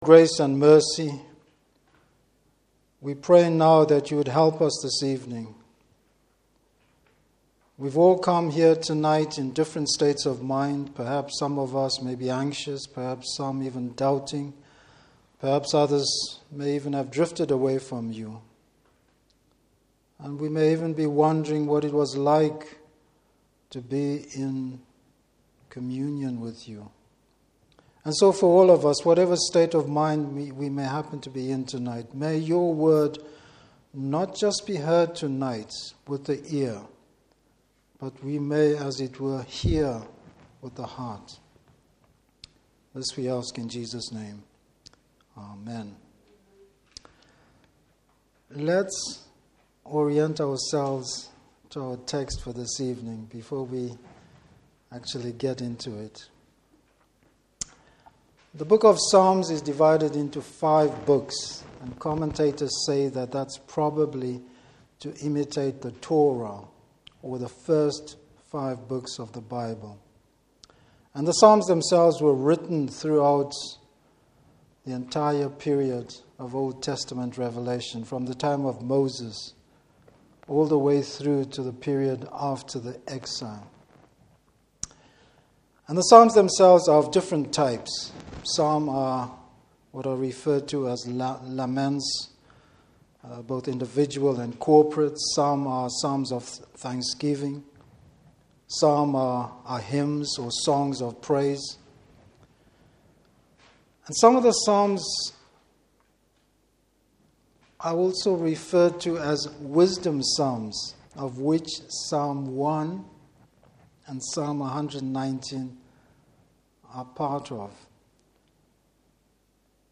Service Type: Evening Service The character of a godly person.